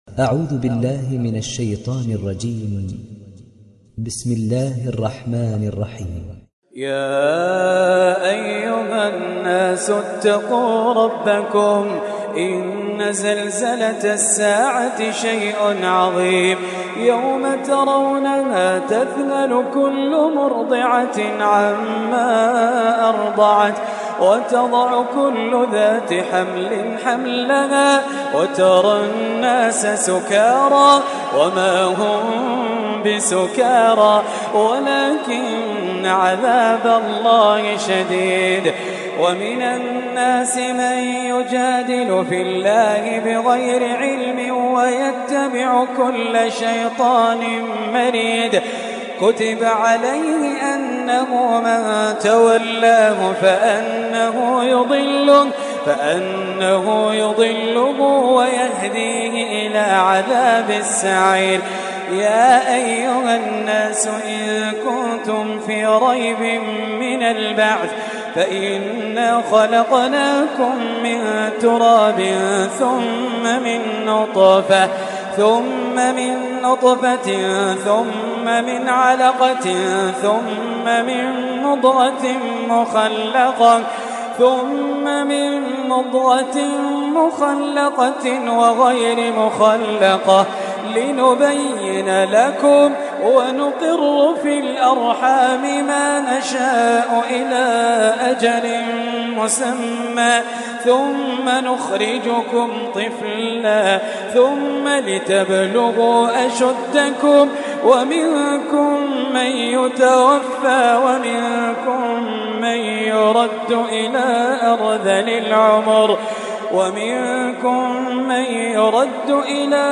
تحميل سورة الحج mp3 بصوت خالد الجليل برواية حفص عن عاصم, تحميل استماع القرآن الكريم على الجوال mp3 كاملا بروابط مباشرة وسريعة